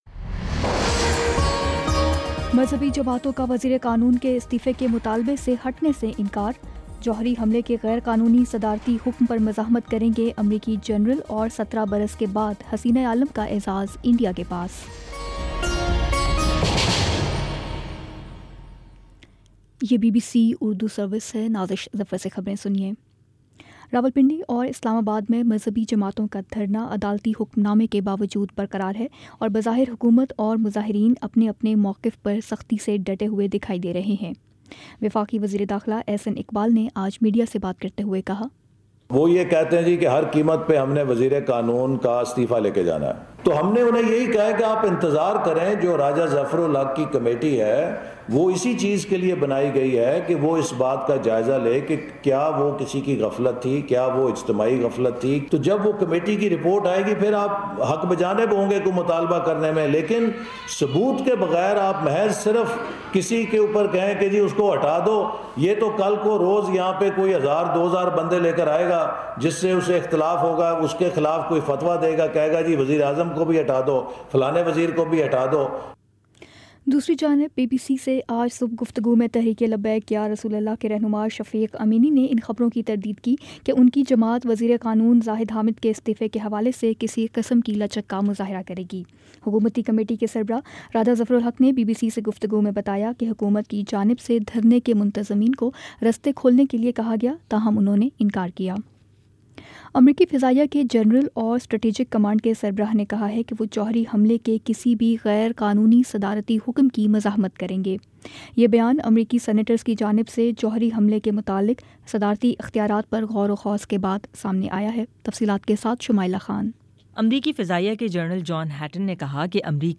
نومبر 19 : شام پانچ بجے کا نیوز بُلیٹن